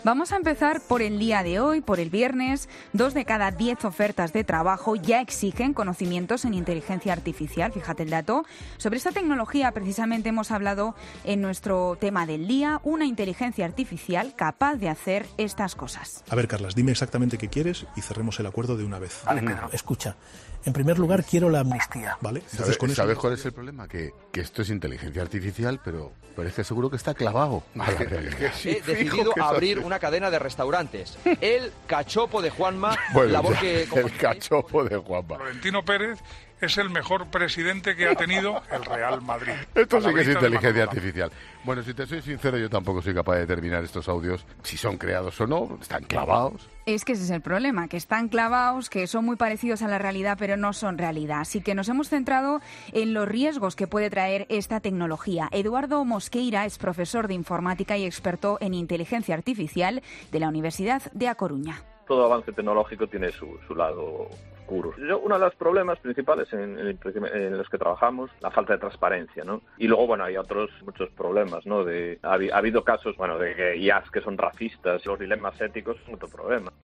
Escucha la reacción de Juanma Castaño al audio de Juanma Castaño, entre lo mejor de La Linterna